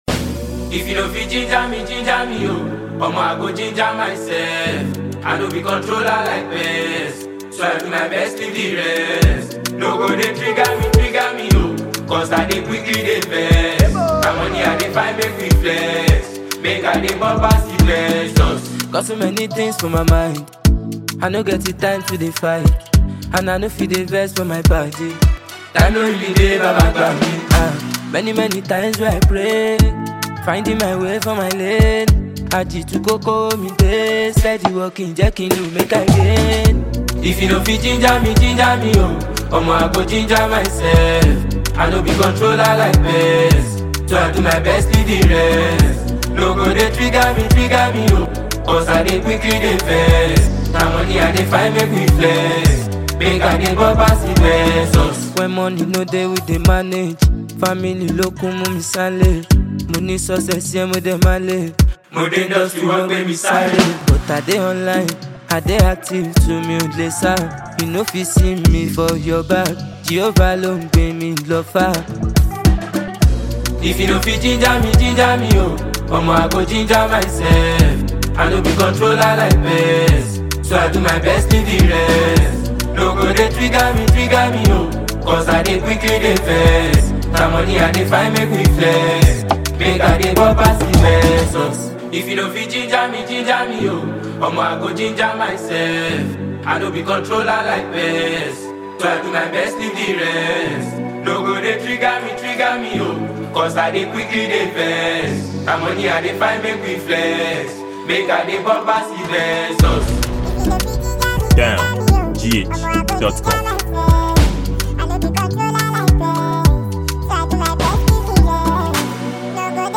It is a captivating afrobeat song available for download.